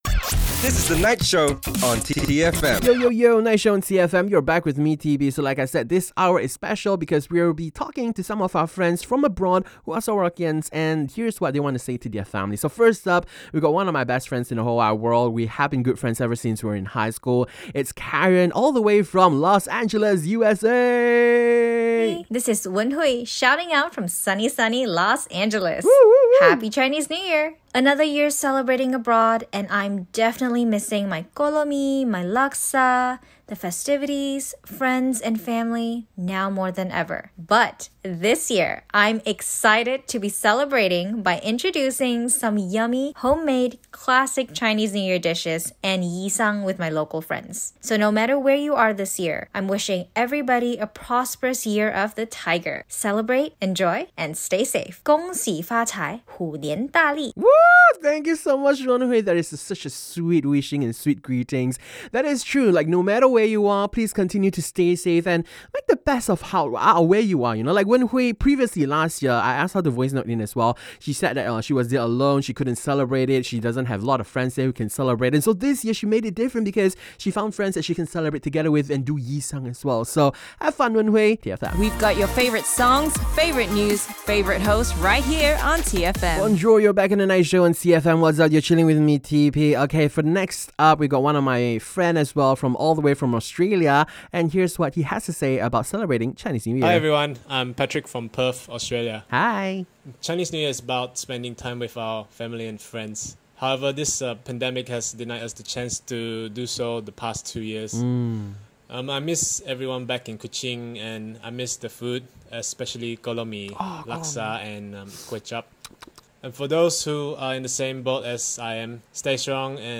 Voice notes from Sarawakians abroad